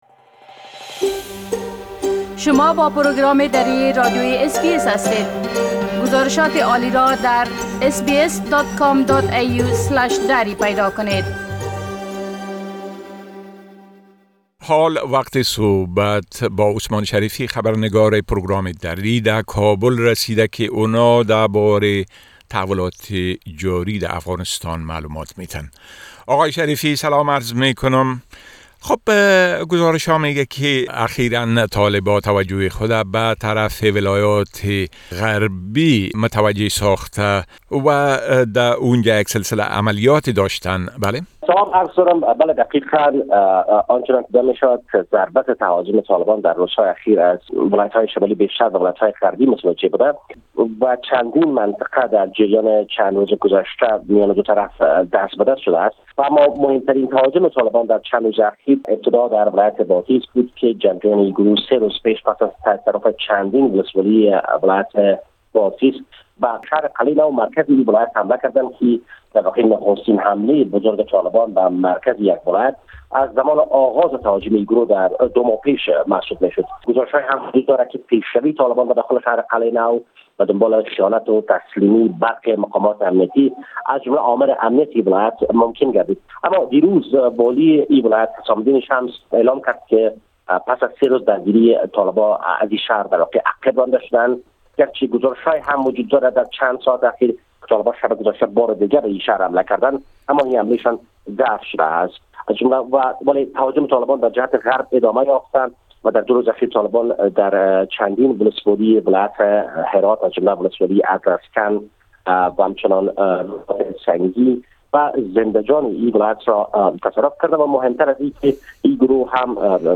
گزارش كامل خبرنگار ما در كابل، به شمول اوضاع امنيتى و تحولات مهم ديگر در افغانستان، را در اينجا شنيده ميتوانيد.